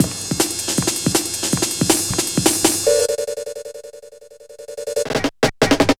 Index of /90_sSampleCDs/Zero-G - Total Drum Bass/Drumloops - 1/track 04 (160bpm)